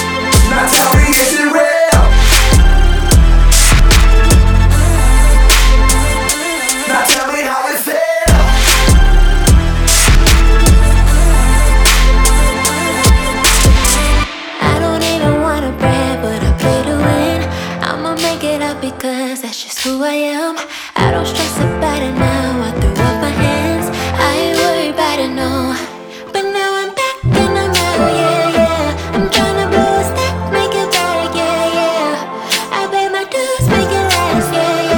Электроника — это про будущее, которое уже здесь.
Electronic Pop
Жанр: Поп музыка / Электроника